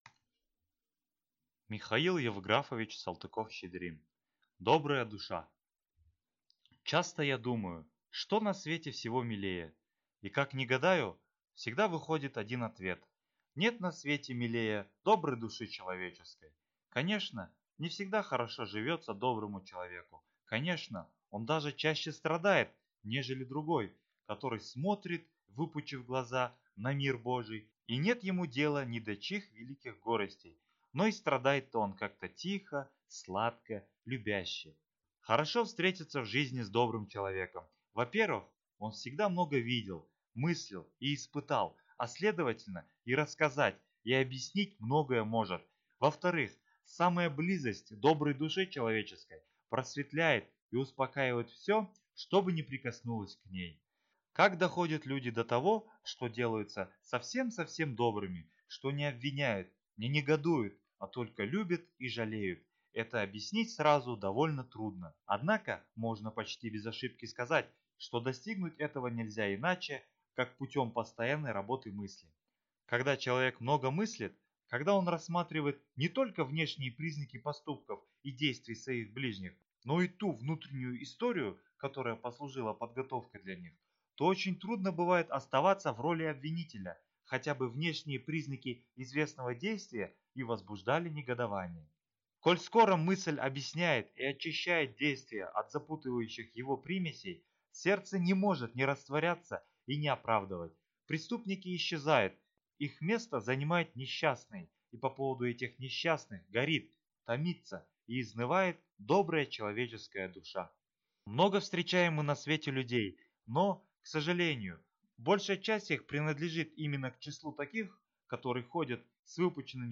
Аудиокнига Добрая душа | Библиотека аудиокниг